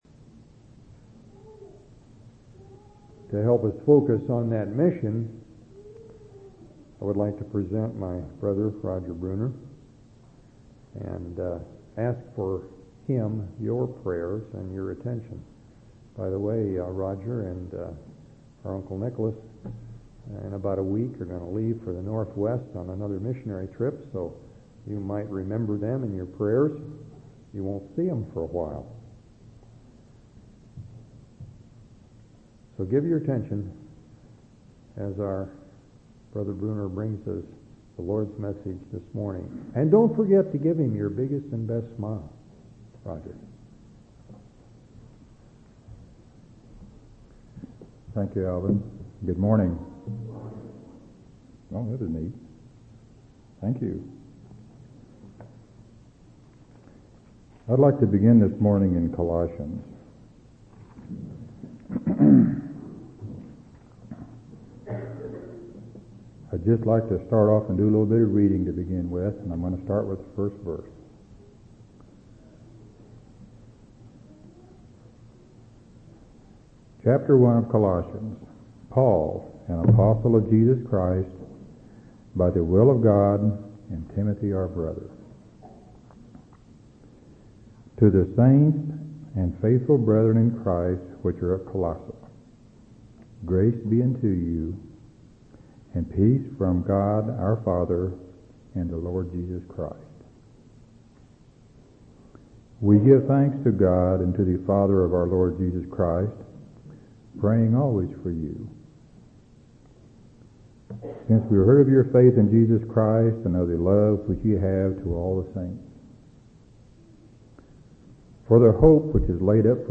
8/24/2003 Location: Temple Lot Local Event